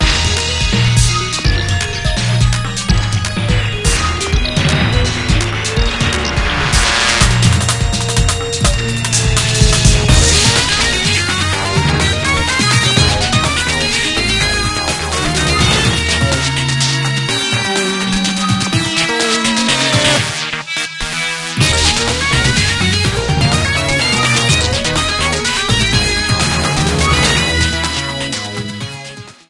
A song
Ripped from the game